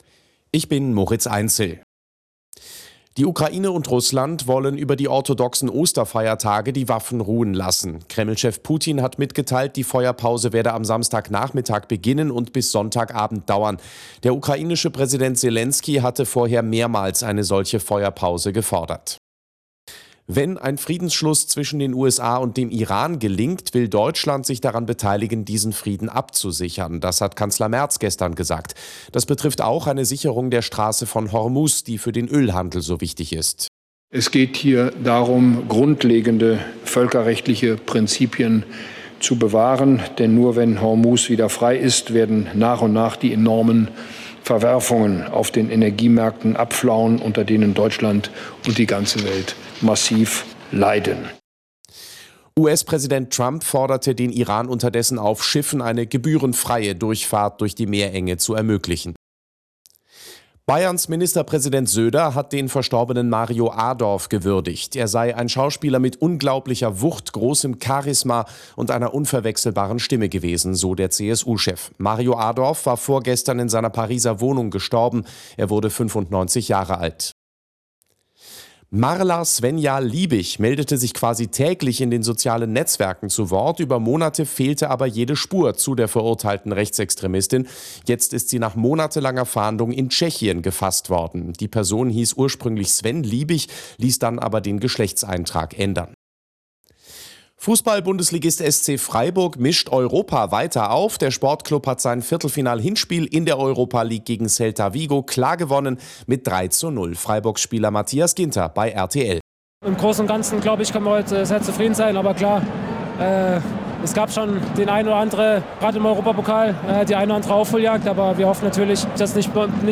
Die aktuellen Nachrichten zum Nachhören